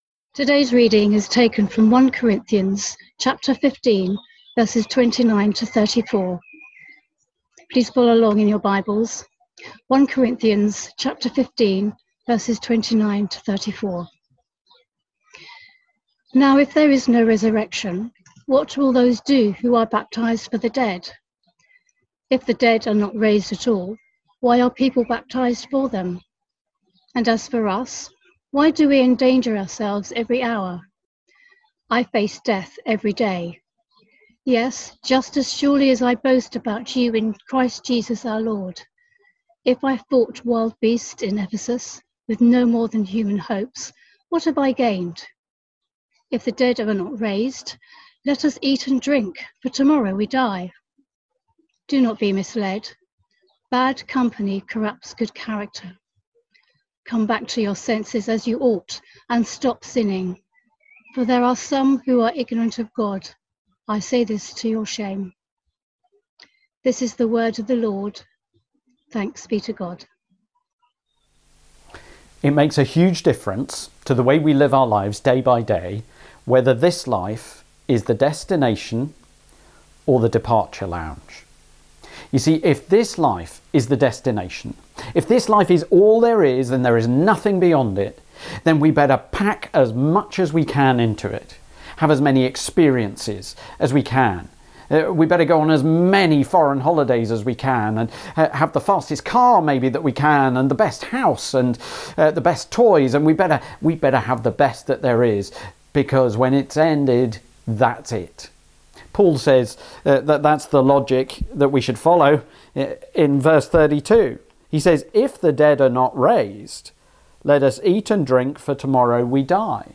Service Type: Streaming